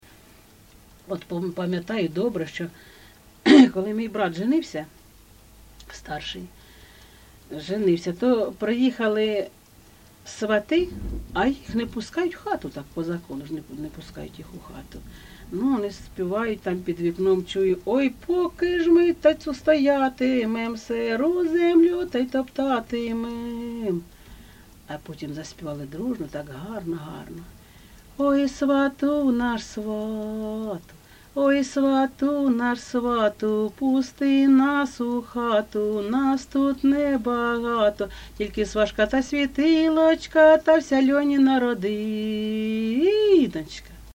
ЖанрВесільні
Місце записус. Серебрянка, Артемівський (Бахмутський) район, Донецька обл., Україна, Слобожанщина